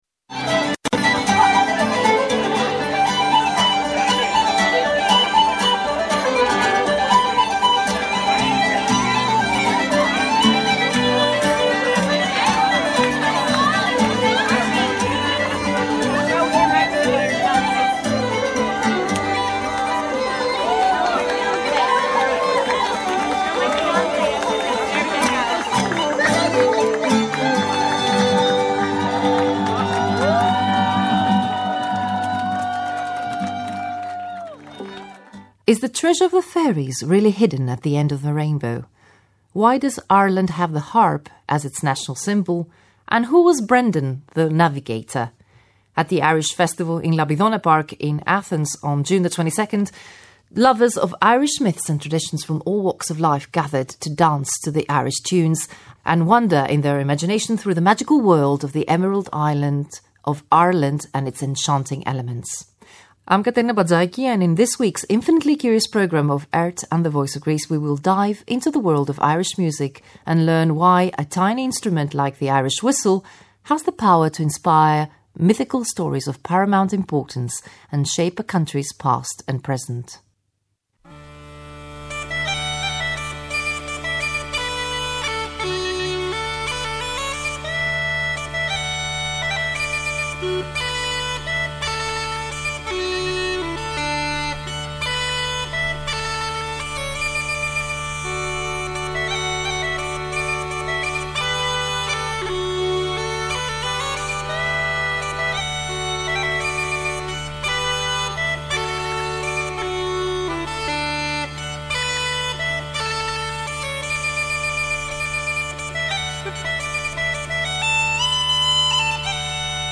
At the Irish festival in Labidona Park in Athens on June 22, lovers of irish myths, and traditions from all walks of life gathered to dance to the Irish tunes , and wander in their imagination through the magical world of the Emerald Island of Ireland and its enchanting elements.